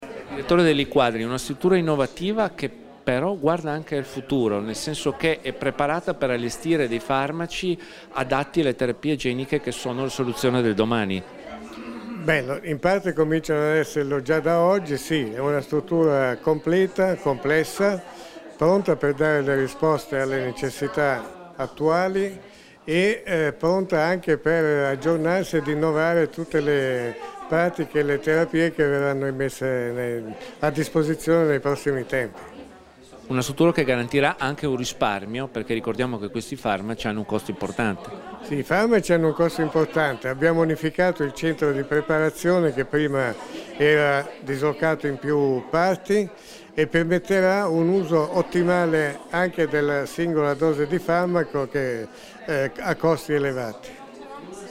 Dichiarazioni di Maria Sandra Telesca (Formato MP3)
all'inaugurazione dell'Unità Farmaci Antiblastici / UFA all'Ospedale Maggiore, rilasciate a Trieste il 14 luglio 2016